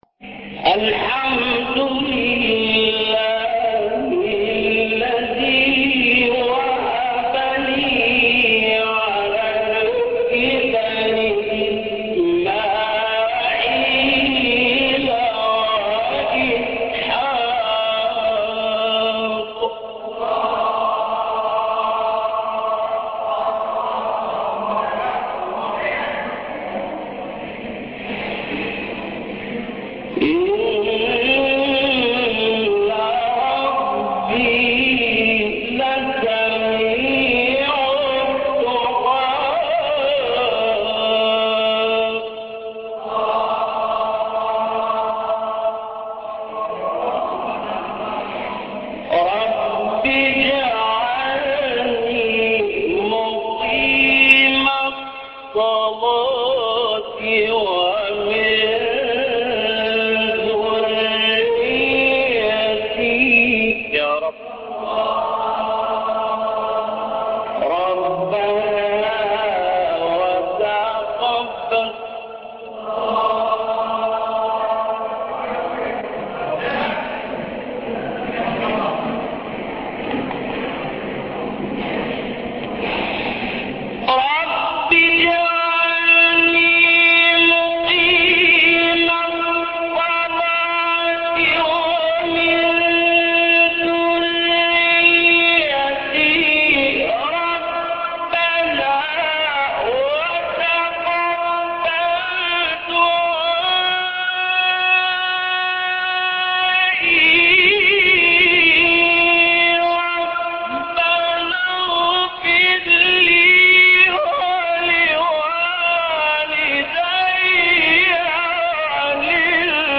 سورة ابراهيم ـ الليثي ـ مقام السيكا - لحفظ الملف في مجلد خاص اضغط بالزر الأيمن هنا ثم اختر (حفظ الهدف باسم - Save Target As) واختر المكان المناسب